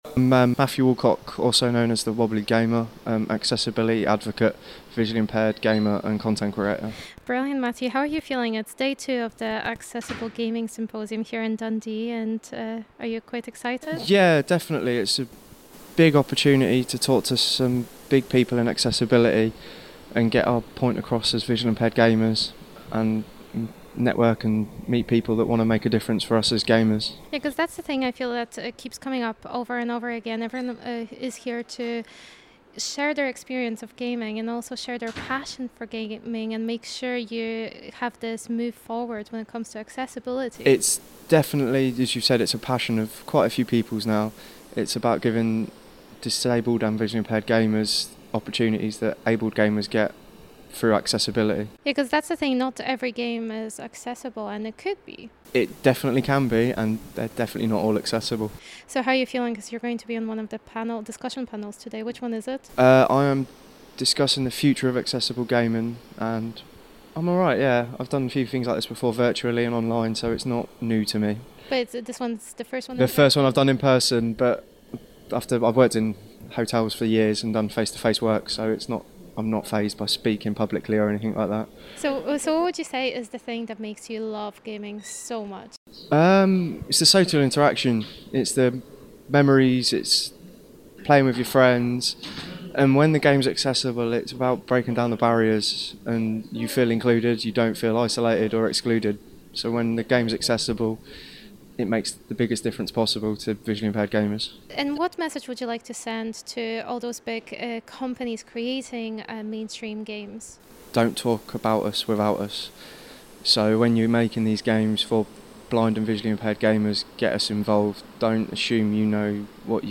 Accessible Gaming Symposium 2022 Interview